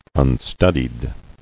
[un·stud·ied]